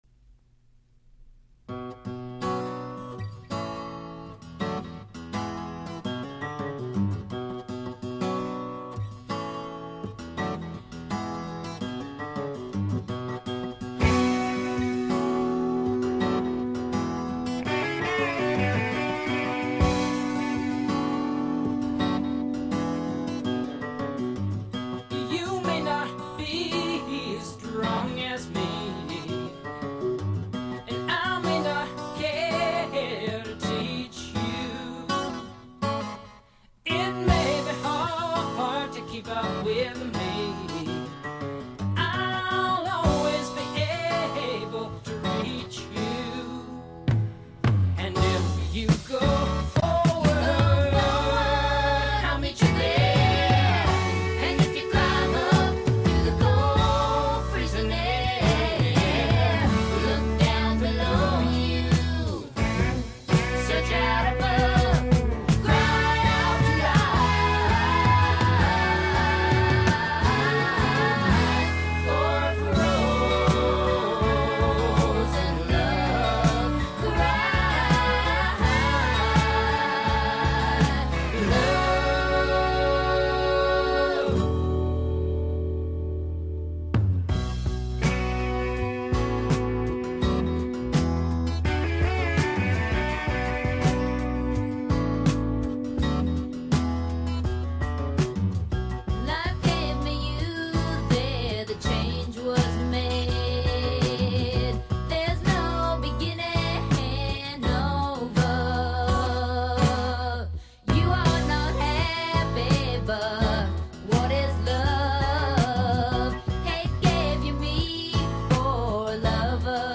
specifically the guitar solo part near the end.